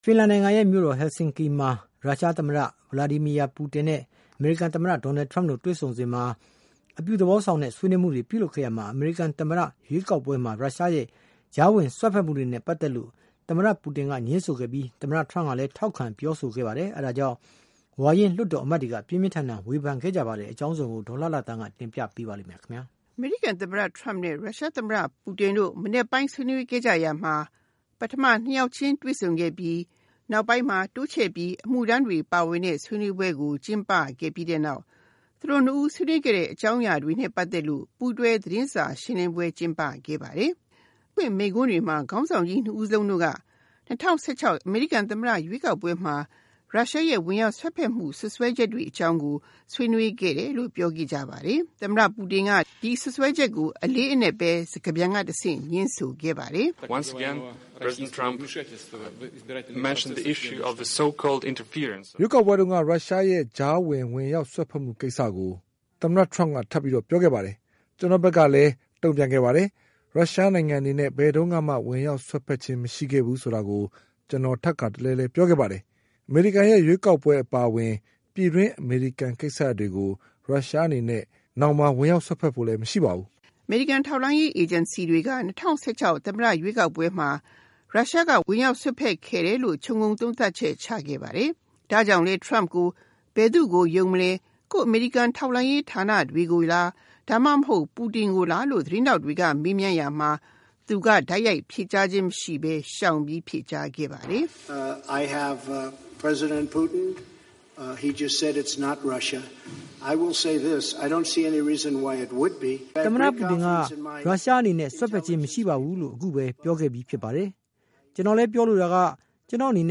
အမေရိကန်သမ္မတ Trumpနဲ့ ရုရှားသမ္မတ Vladimir Putin က မနက်ပိုင်းဆွေးနွေးခဲ့ကြရမှာ ပထမ ၂ ယောက်ခြင်းတွေ့ဆုံခဲ့ပြီး နောက်ပိုင်းမှာ တိုးချဲ့ပြီး အမှုထမ်းတွေပါဝင်တဲ့ ဆွေးနွေးပွဲကို ကျင်းပပြီးတဲ့နောက် သူတို့ ၂ ဦးရဲ့ ဆွေးနွေးမှုအကြောင်းအရာနဲ့ ပတ်သက်လို့ ပူးတွဲသတင်းစာရှင်းလင်းပွဲ ကျင်းပခဲ့ပါတယ်။ သူတို့မိန့်ခွန်းတွေမှာ ခေါင်းဆောင် ၂ ယောက်တို့က ၂၀၁၆ အမေရိကန်သမ္မတရွေးကောက်ပွဲမှာ ရုရှားရဲ့ ဝင်ရောက်စွက်ဖက်မှုတွေကို စွပ်စွဲမှုတွေကို ဆွေးနွေးခဲ့တယ်လို့ ပြောခဲ့ကြပါတယ်။ ရုရှားသမ္မတ Vladimir Putinက ဒီစွပ်စွဲချက်ကို စကားပြန်ကတဆင့် ငြင်းဆိုခဲ့ပါတယ်။